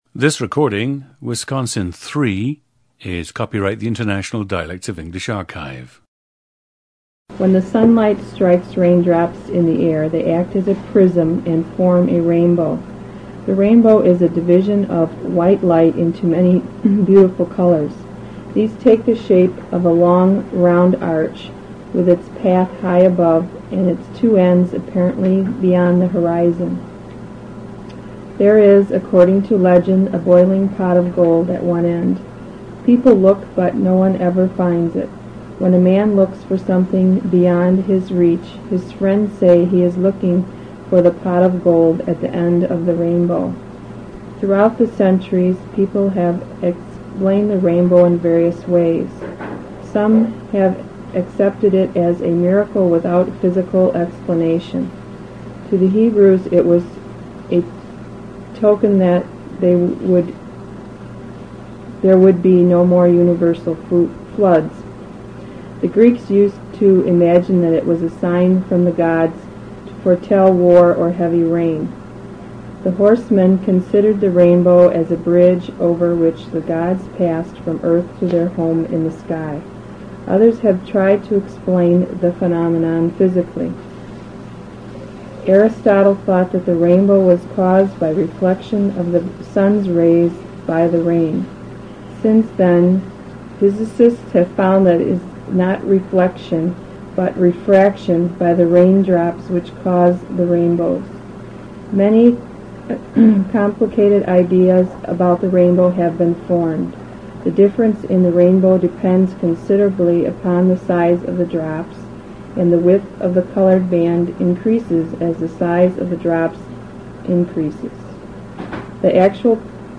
AGE: 30s
GENDER: female
You will hear a tense fronted vowel in “arch” and “part” with strong “r” coloration; a much closer than GenAm vowel in “rain,” “day,” etc.; but not such a close vowel as Wisconsin 2 shows in “drop,” “hospital,” etc. This could be a generational difference.
• Recordings of accent/dialect speakers from the region you select.